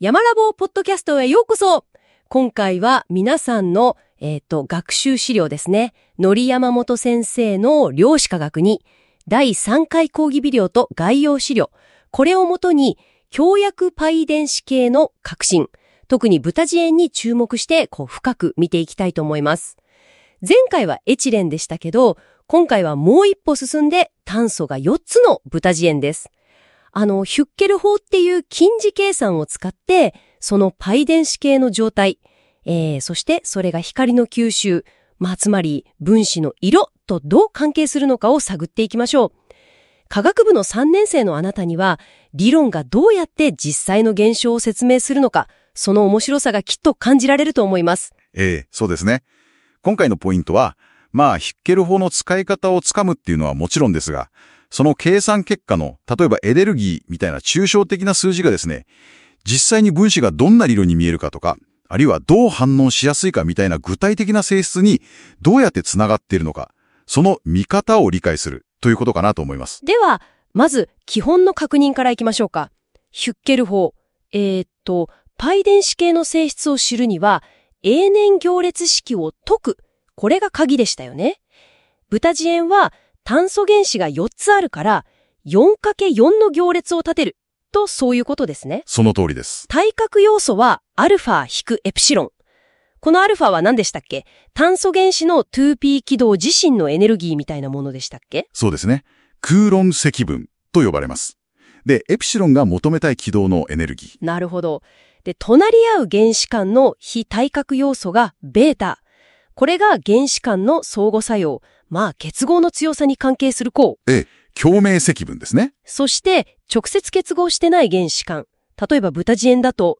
量子化学２（第３回; 2020年版）の「過去の講義動画 」をもとに、講義の主要なトピックについて「２人のＡＩホストがおしゃべりする」というポッドキャスト風の音声概要を作りました。